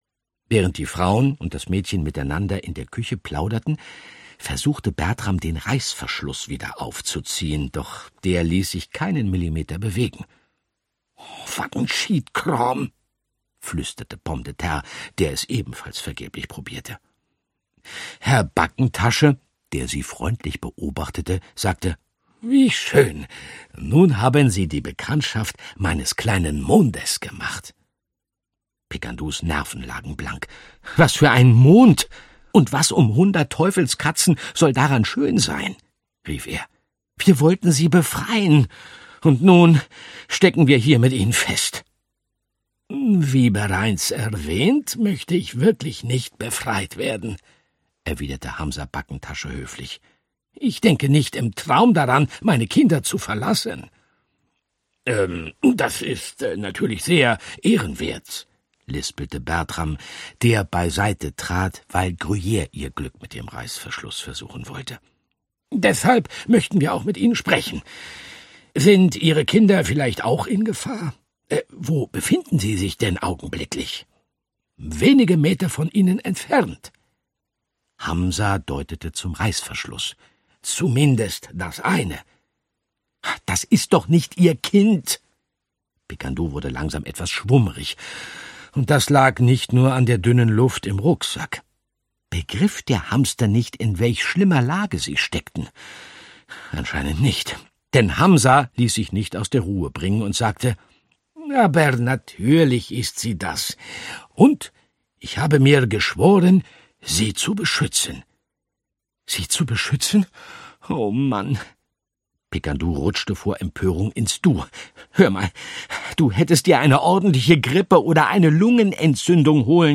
Andreas Fröhlich (Sprecher)
Ungekürzte Lesung